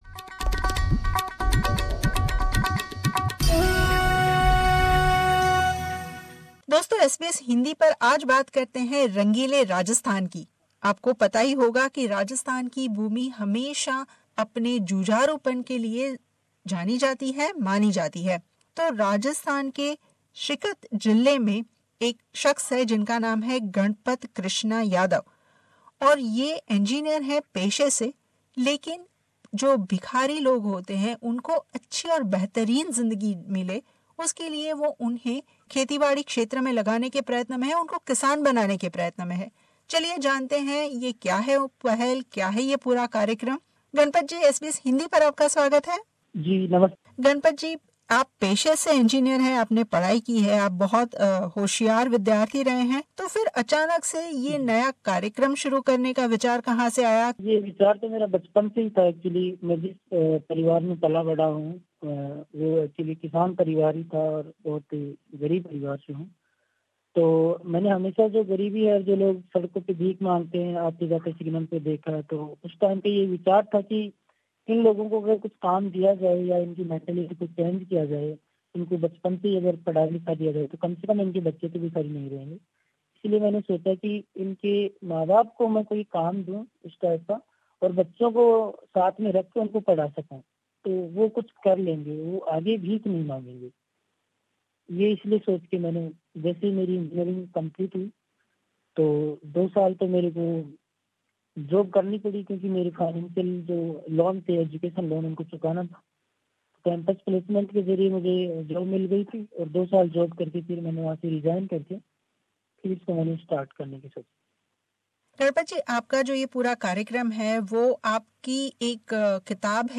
मुलाकात